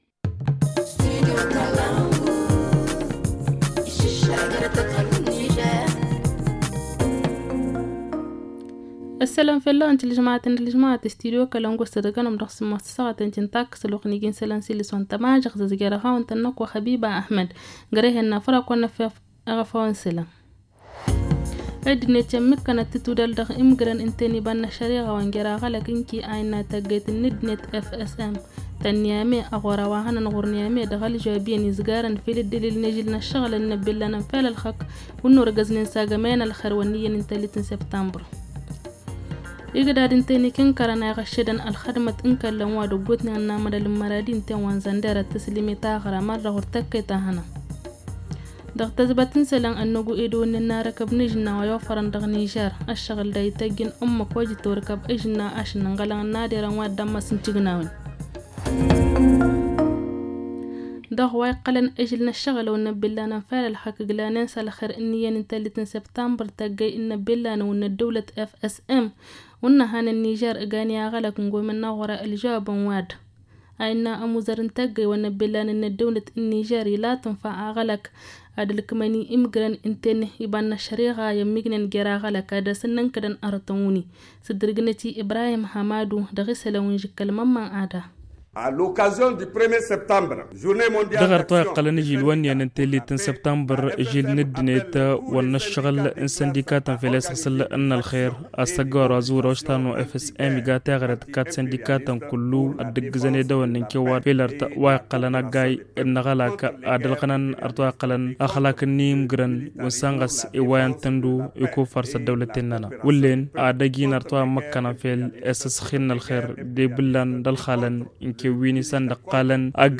Le journal du 1 septembre 2022 - Studio Kalangou - Au rythme du Niger